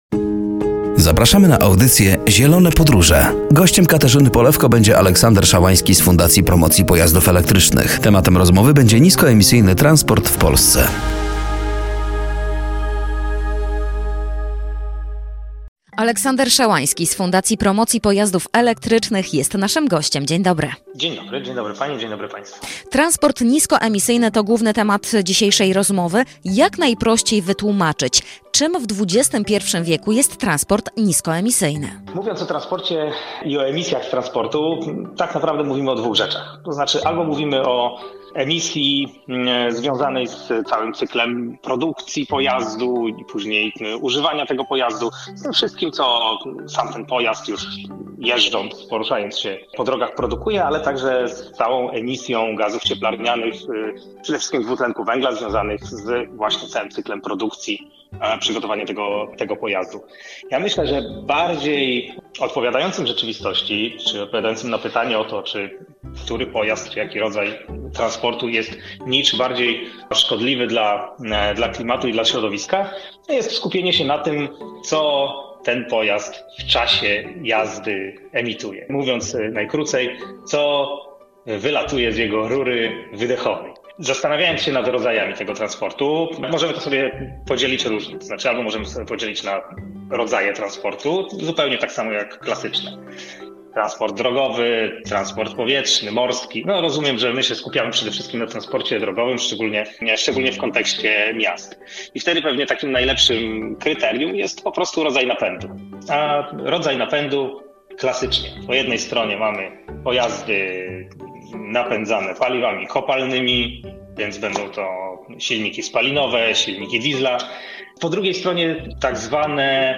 W dzisiejszej (6.12.) audycji